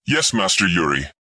I optimized the streamlined configuration of the previously produced Lasher Tank and equipped it with a new Lasher Tank voice, which is generally consistent with the tone of the original dialogue.
Voicelines sound a little bit AI, but good.